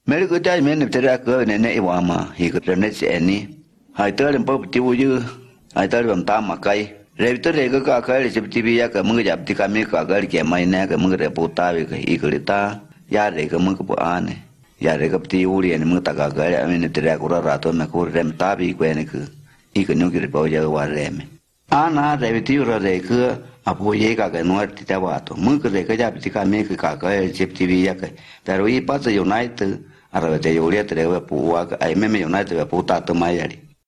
29 November 2018 at 11:56 am The nasalized vowels, simple tones, simple consonants, simple syllable structure, and prevalence of high, non-front unrounded vowels ([ɨ] or [ɯ]) makes me think of South American languages.